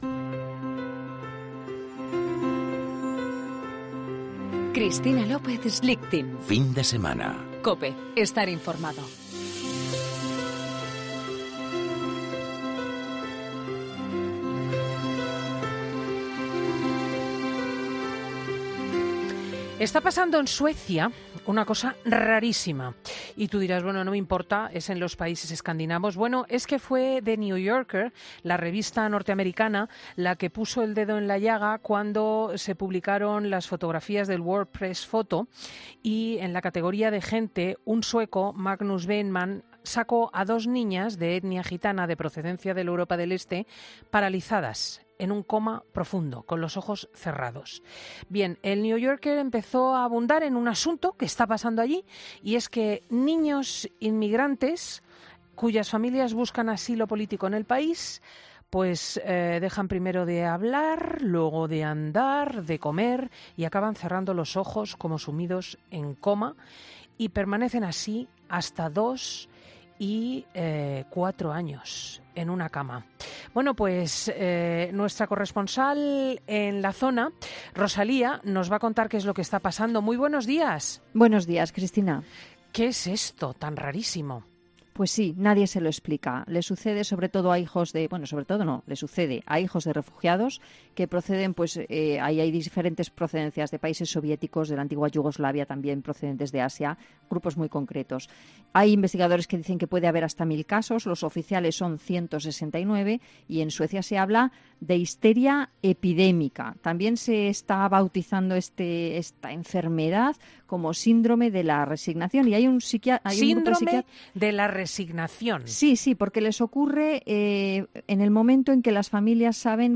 EN "FIN DE SEMANA COPE"